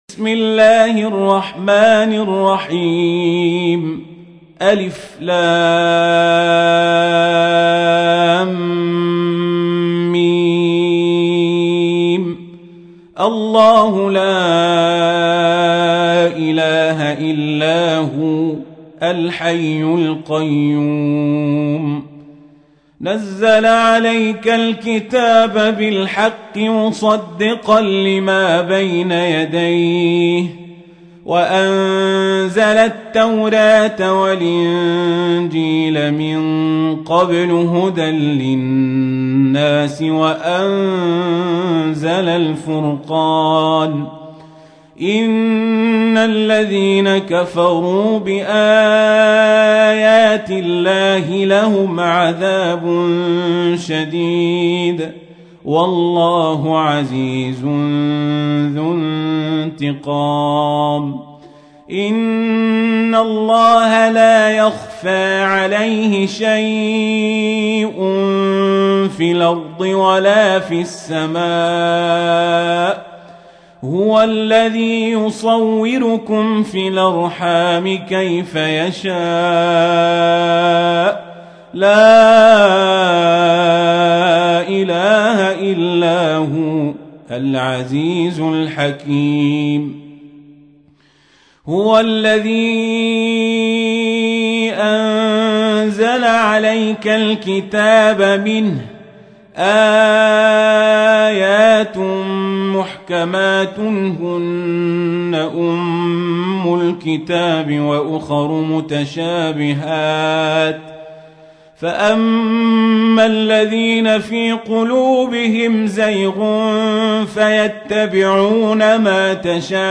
تحميل : 3. سورة آل عمران / القارئ القزابري / القرآن الكريم / موقع يا حسين